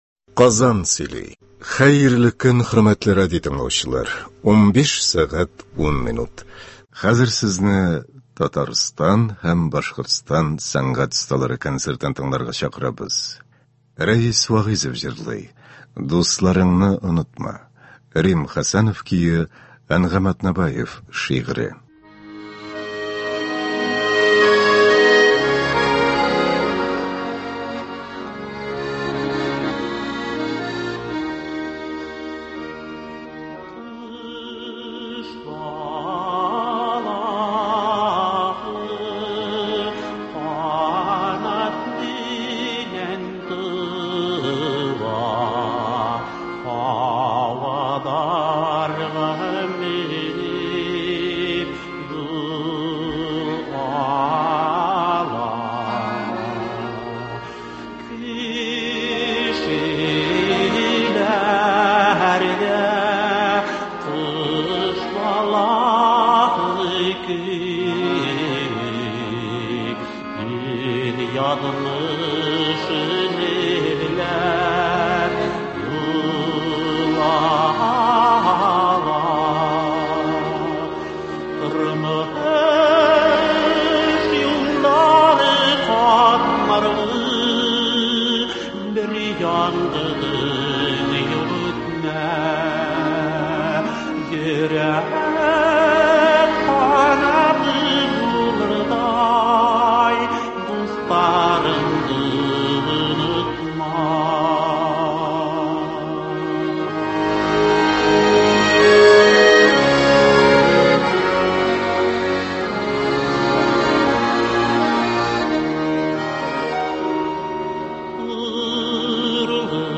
Татарстан һәм Башкортстан сәнгать осталары концерты.